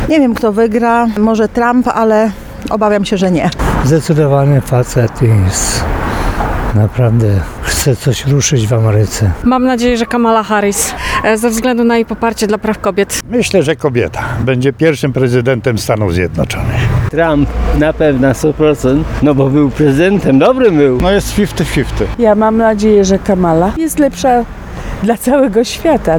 Zapytaliśmy tarnowian kto ich zdaniem ma większe szanse na wygraną. Tu podobnie jak wśród Amerykanów głosy są podzielone.